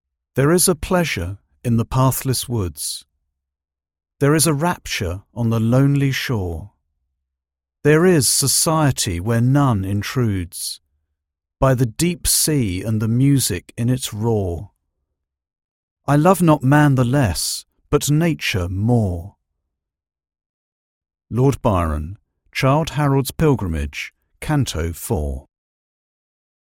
English speaker, BBC, RP, educated, smooth, clear, sophisticated, stylish, precise
Sprechprobe: Sonstiges (Muttersprache):
I have a stylish, precise English voice, educated and sophisticated.